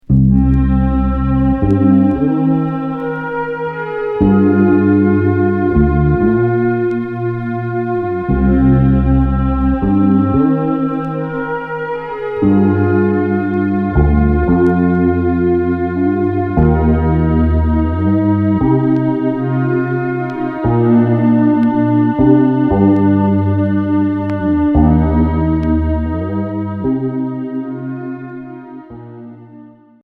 (Instrumental)
Cold pop Deuxième 45t retour à l'accueil